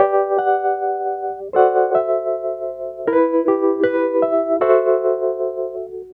Track 07 - Wurlitzer.wav